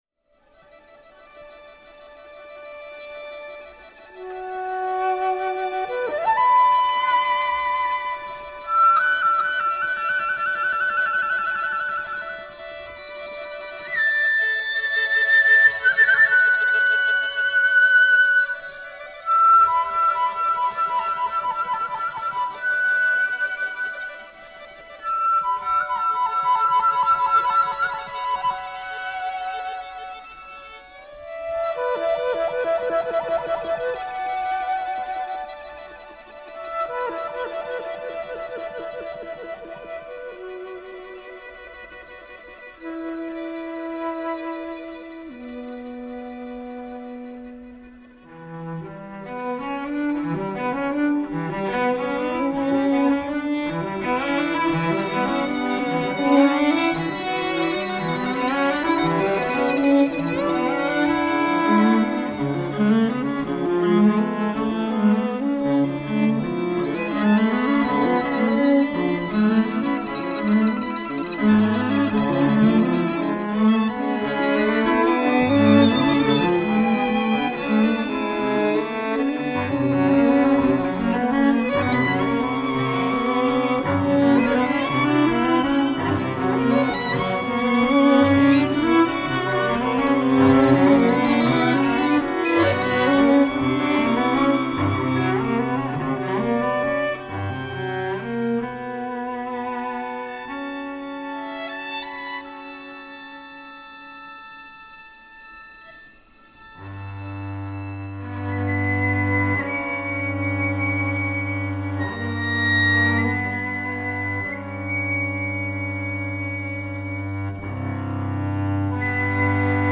string quartet
viola
violin
cello
fuse uptown panache with downtown genre mashup.